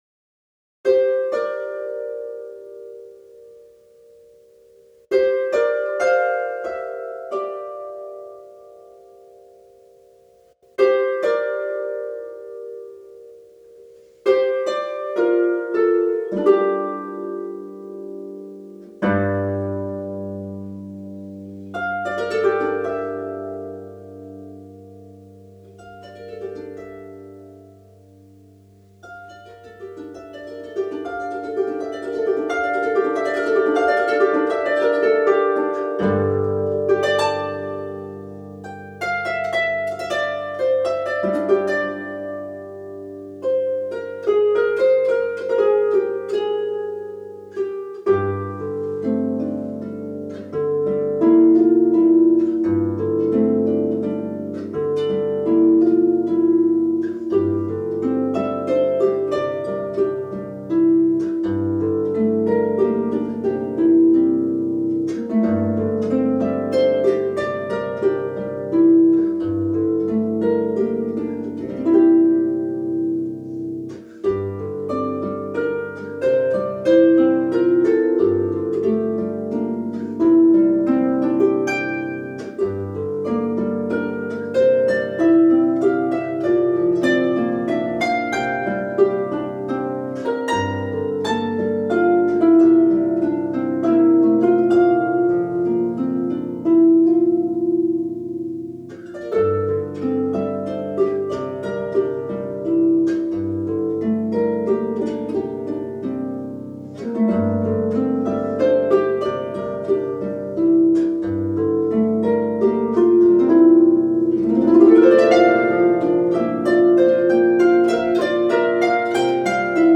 A beautiful, ethereal piece!